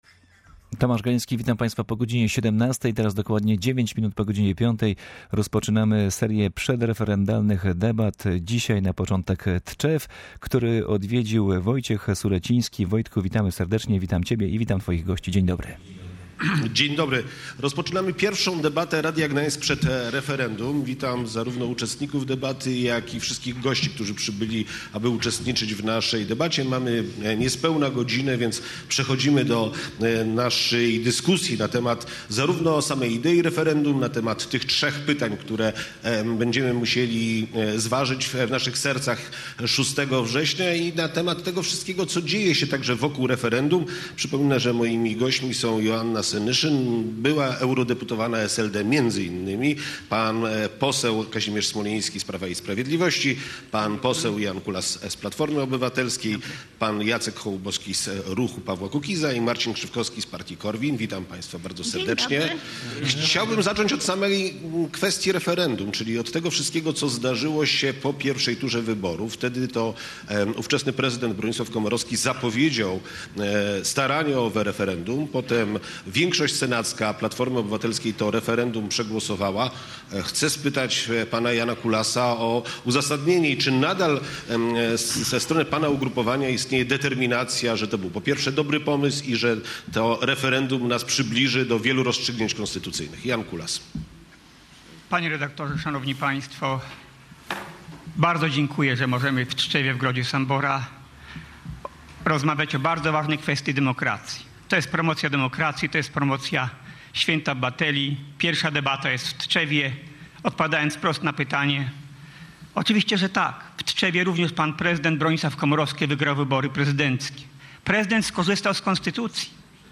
Jednomandatowe okręgi wyborcze, finansowanie partii politycznych i rozstrzyganie wątpliwości w sprawach podatkowych na korzyść podatnika- to zagadnienia, które mają pojawić się w referendum 6 września. Radio Gdańsk organizuje cykl debat, w czasie których pomorscy politycy dyskutują o treści referendum. Pierwsze spotkanie odbyło się w tczewskiej Fabryce Sztuk.
debata_26_08.mp3